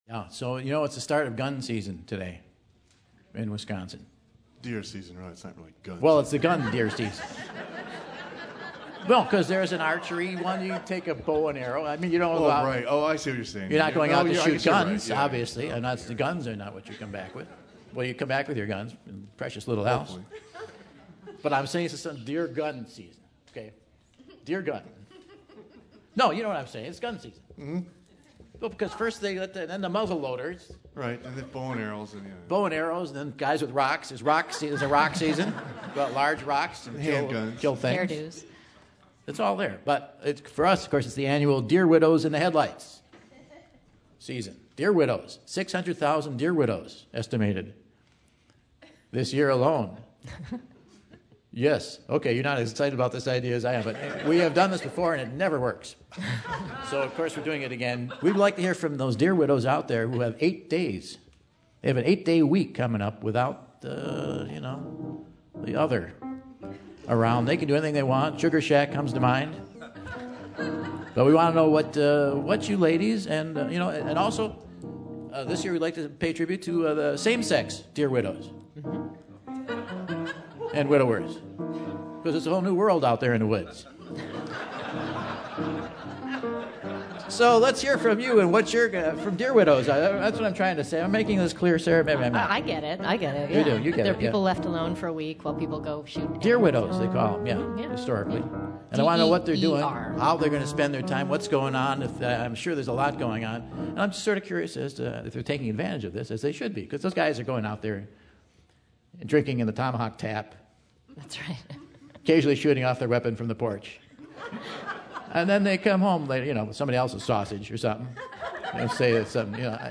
It's estimated that 600,000 "deer widows" are alone for eight days during deer hunting season! We hear from them on how their spending their time!